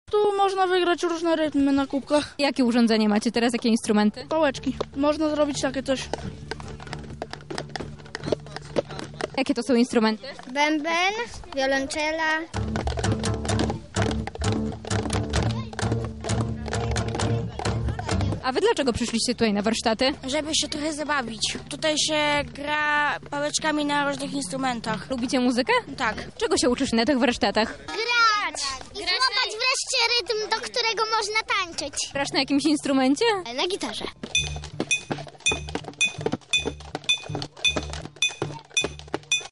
Na miejscu była nasza reporterka